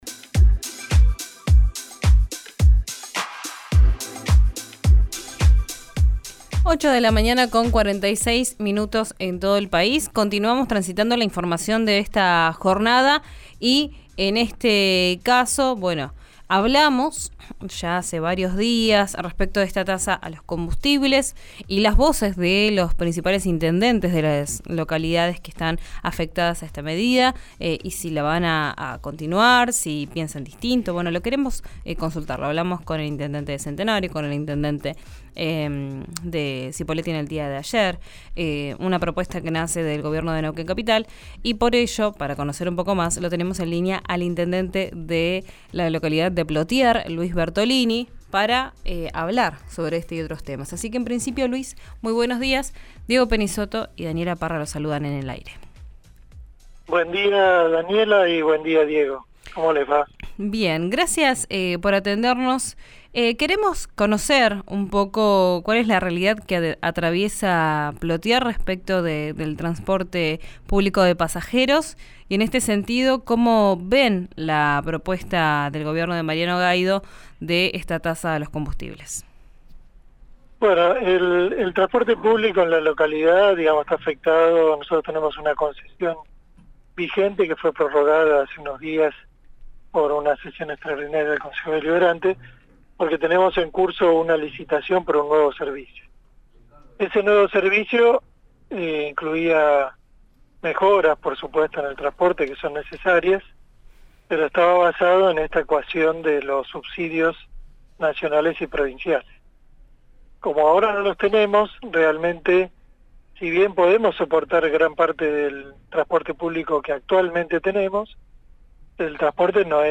Escuchá al intendente Luis Bertolini en RÍO NEGRO RADIO: